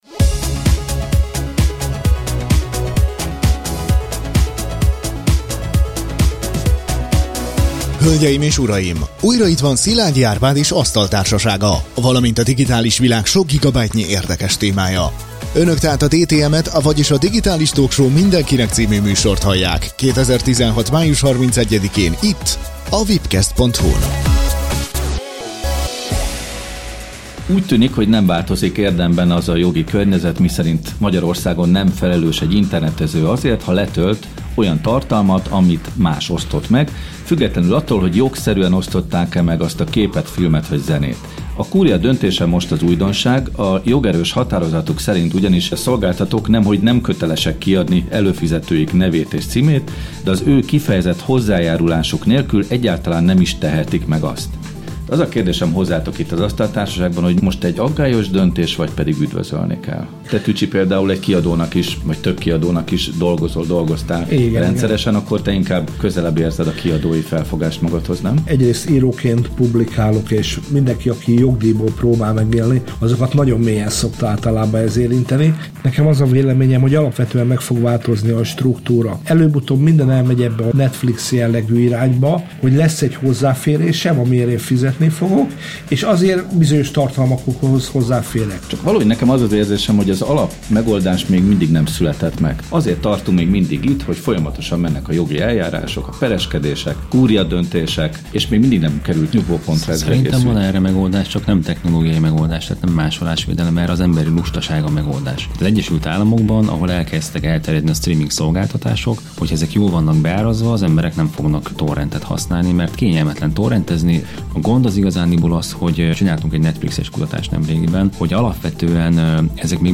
A korábbi Legfelsőbb Bíróságnak megfelelő Kúria jogerős határozata szerint hiába is kérnék a filmforgalmazók vagy zenei kiadók a torrentezők adatait, az internetszolgáltató nem köteles azokat kiadni, sőt a torrent-használók kifejezett hozzájárulása nélkül egyáltalán nem is adhatják ki. Erről beszélget a műsor elején az asztaltársaság.